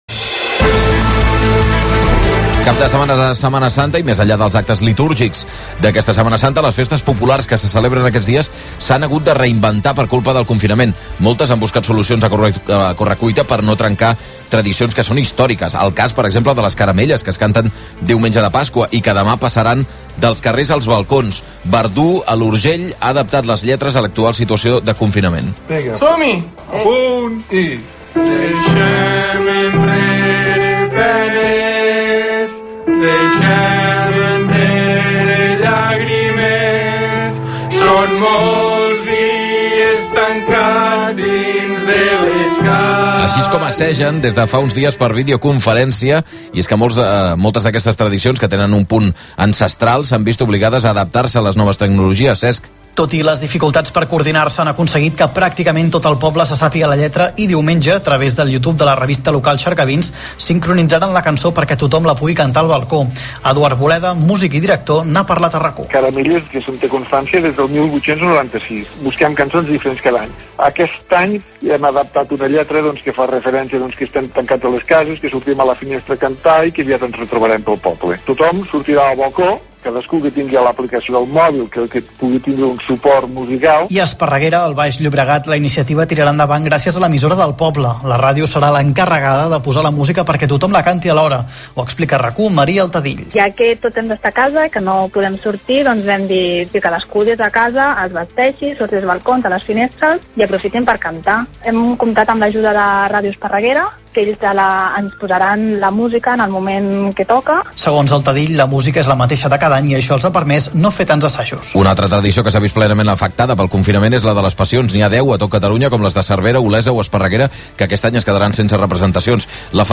Entrevista a RAC1